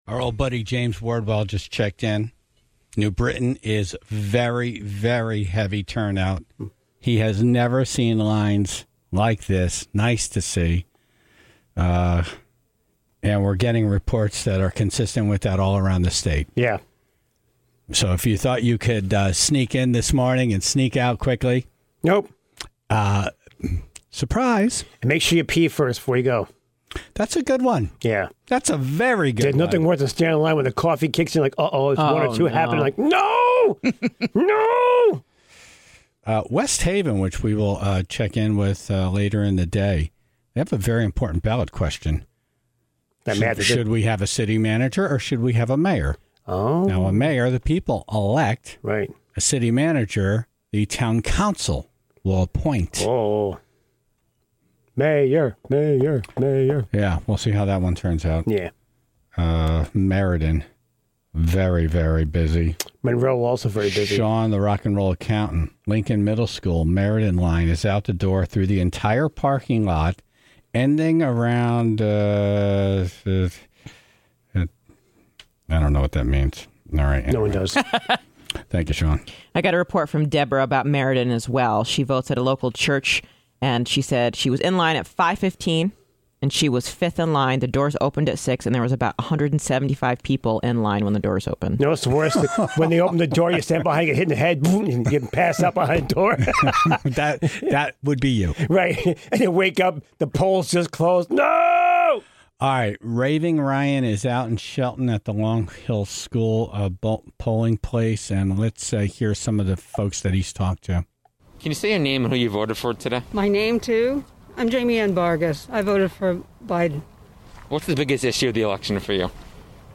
He even encountered a man playing the trumpet to keep peoples' spirits up!